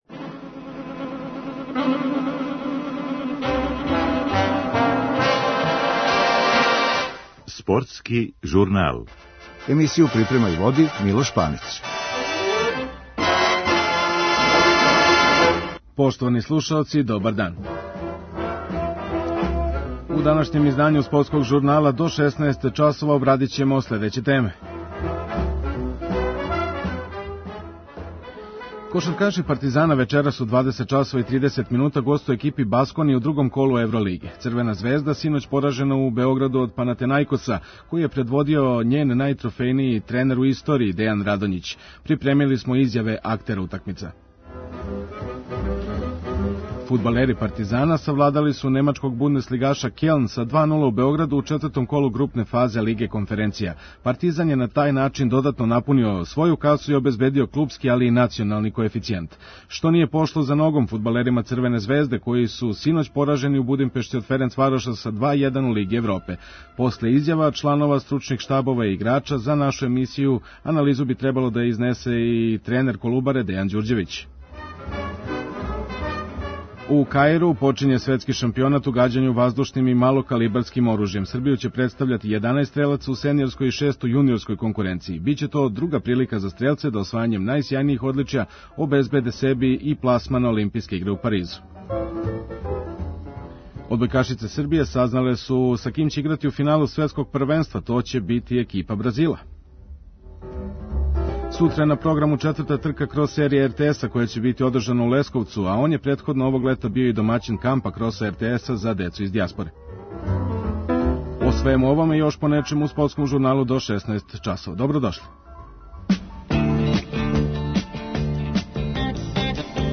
Припремили смо изјаве актера утакмица.